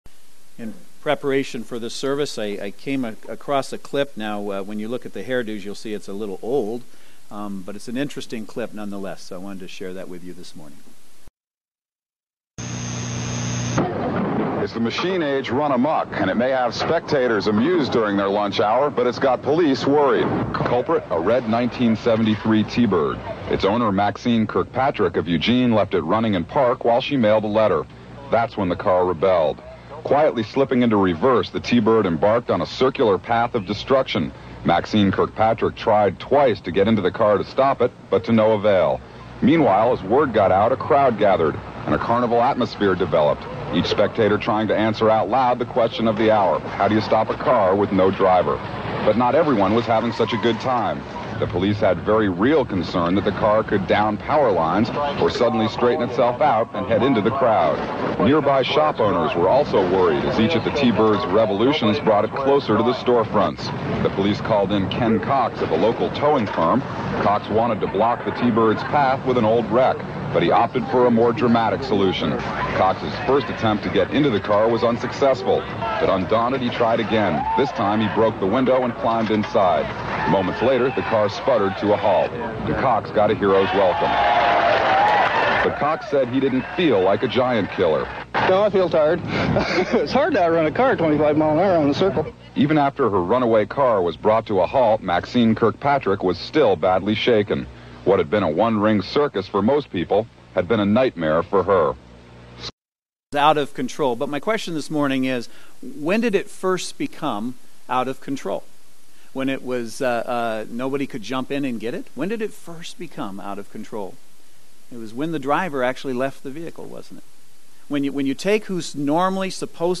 Series: Names of God Service Type: Saturday Worship Service Speaker